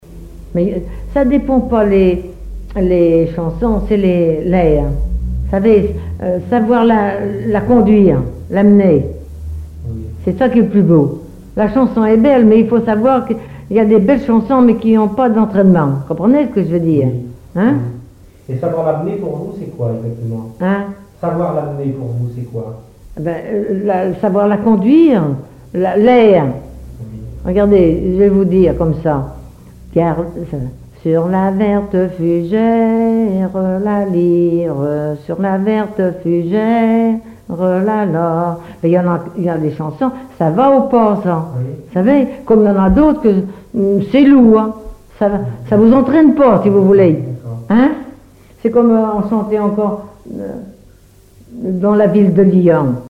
Témoignages et chansons traditionnelles
Catégorie Témoignage